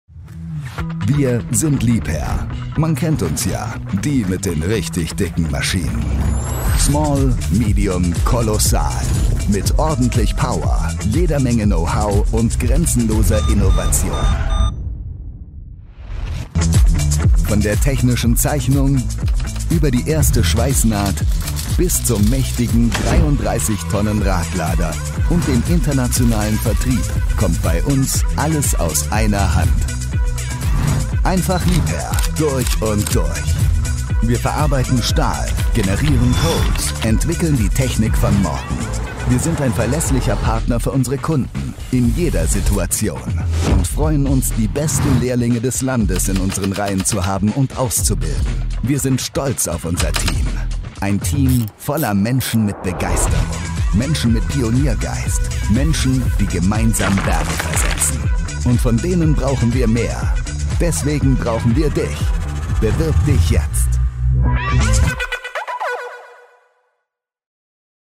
Dank seiner angenehmen sonoren Tonlage kommt er bei den Kunden immer hervorragend an.